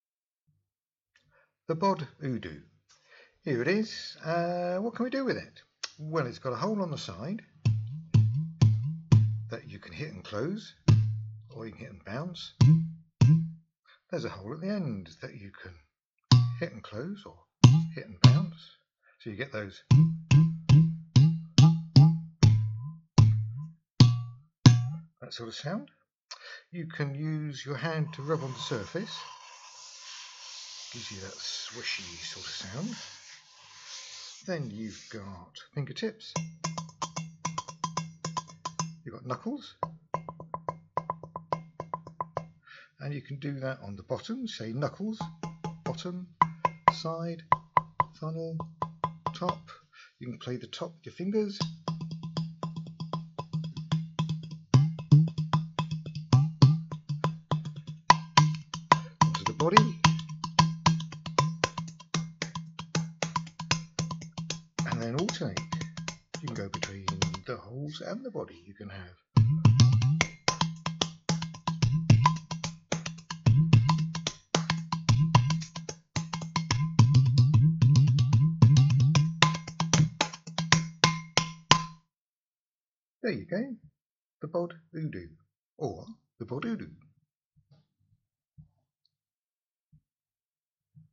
The word “Udu” means “pot” in the language of the Igbo people of Nigeria, and what musicians know as an Udu is a ceramic percussion instrument in the form of a bellied pot with a neck, typically with one hole at the end of the neck and one on the side of the belly.
First, by stopping or un-stopping either of the holes with the palm of your hand, fingers arched back slightly, you create a suction effect which pushes out or draws in the air through the pot, making all kinds of lovely bass notes which bend.
You can gently brush the flat surfaces of your palm or fingers over the surface of the Udu to create a brushing or shakey kind of sound.
BOD-Udu-narrated-at-BODdrums.mp3